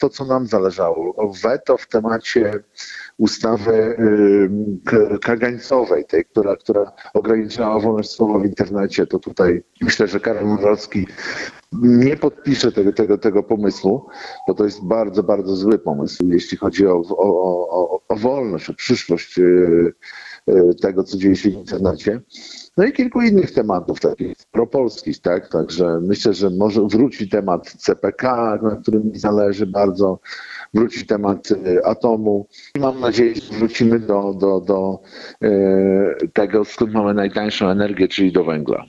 – Cieszę się, że wygrał człowiek, który podpisał Deklarację Toruńską u Sławomira Mentzena – mówił w Radiu 5 Michał Połuboczek, poseł Konfederacji. W powyborczym komentarzu poseł stwierdził, że jest dobrej myśli po wygranej Karola Nawrockiego. Parlamentarzyście chodzi o to, że kandydat popierany przez Prawo i Sprawiedliwość poparł postulaty Konfederacji.